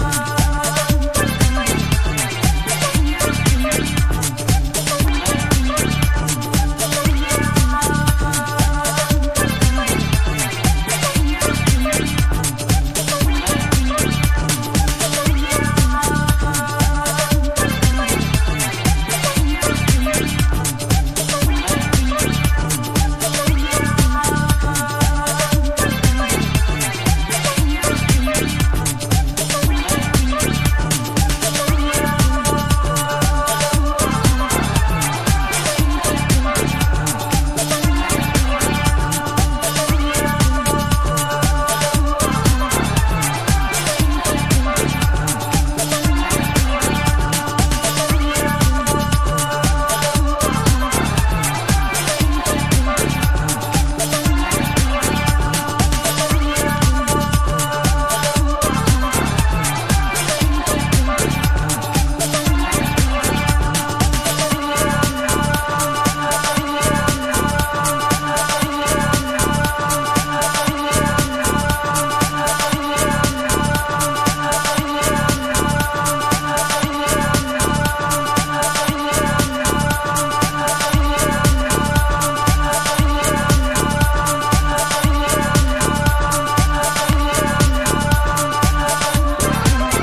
エディットの妙が活きまくったアシッド且つ粘着質なキラー・ブギーディスコ。
NU-DISCO / RE-EDIT